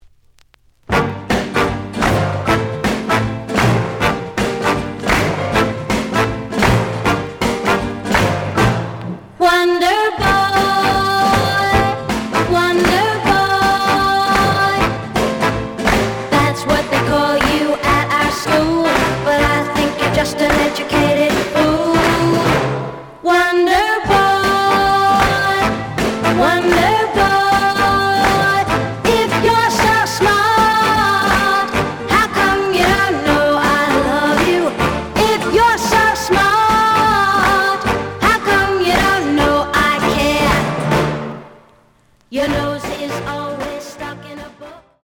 The audio sample is recorded from the actual item.
●Genre: Rock / Pop
B side plays good.